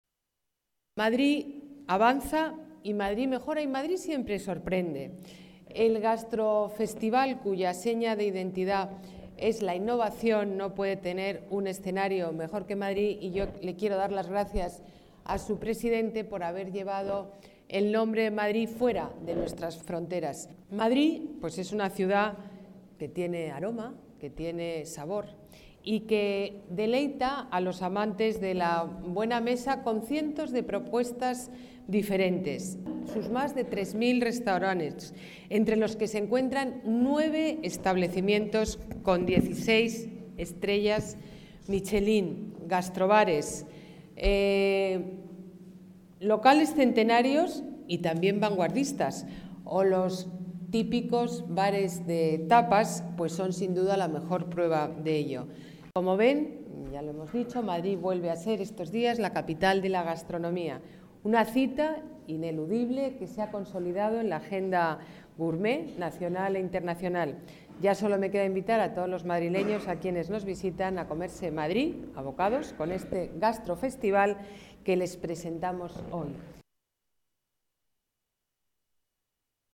Vídeo de presentación Más documentos Discurso de Ana Botella en la presentación de Gastrofestival